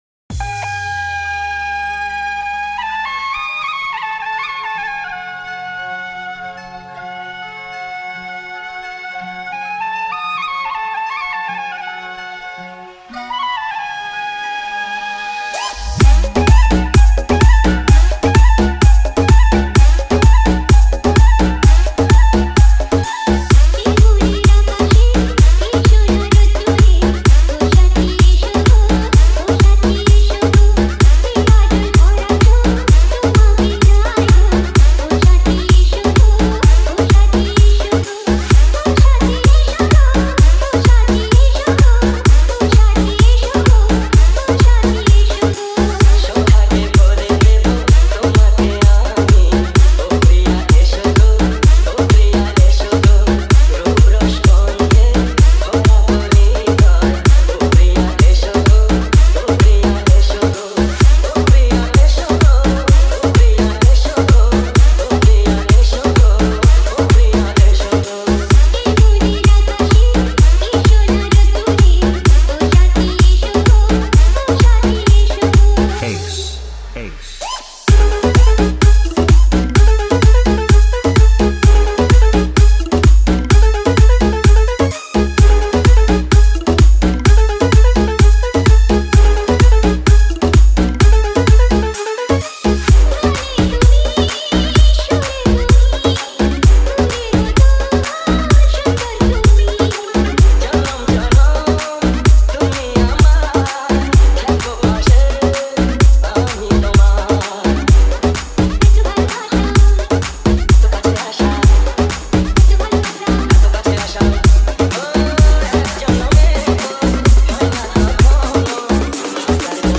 Edm Humming Bass Mix